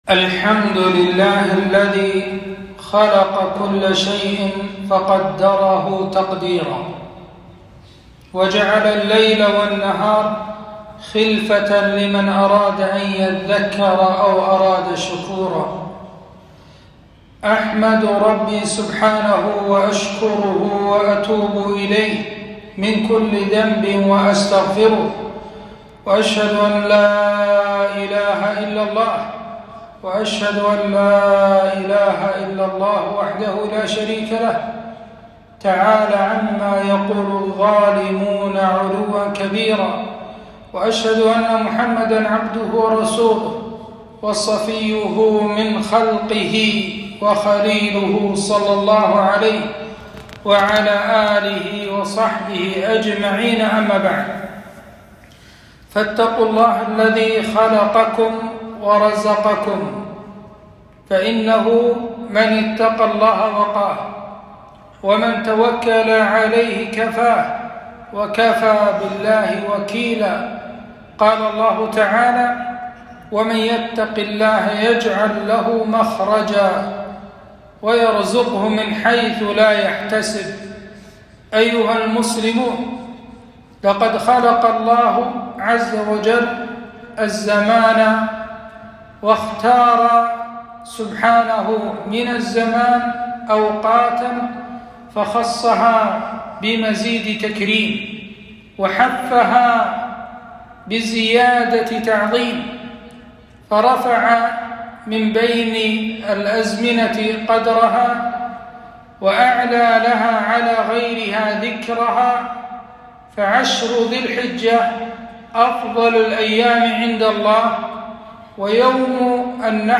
خطبة - فضل شهر الله المحرم ويوم عاشوراء